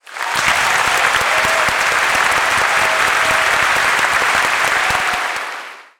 applause-s.wav